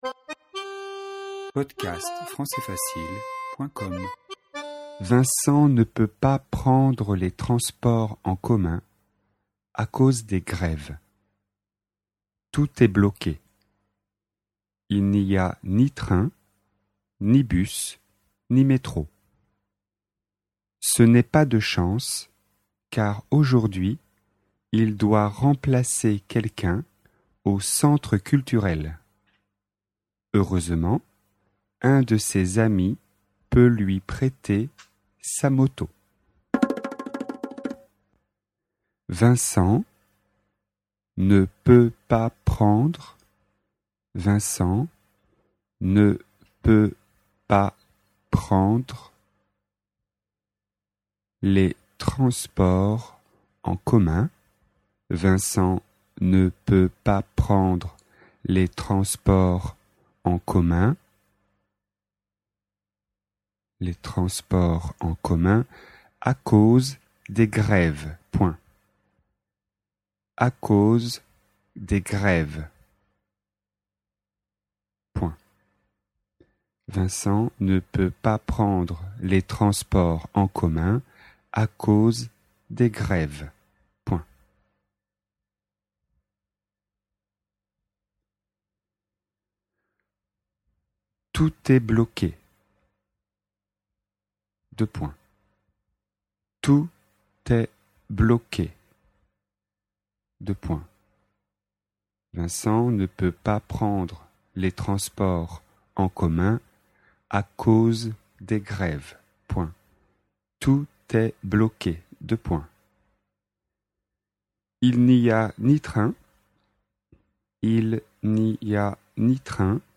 Dictée, niveau débutant (A1), sur le thème transport et grève.